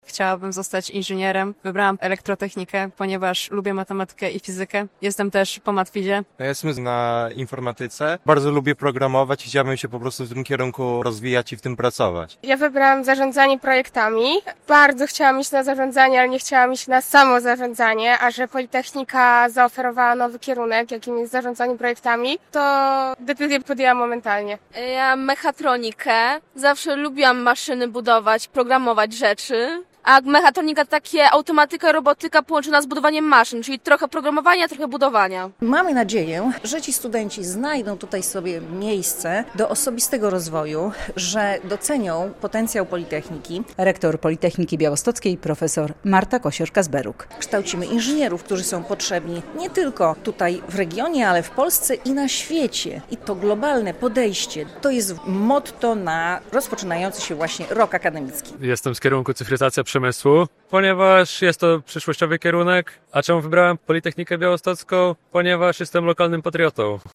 Radio Białystok | Wiadomości | Wiadomości - Politechnika Białostocka uroczyście rozpoczęła rok akademicki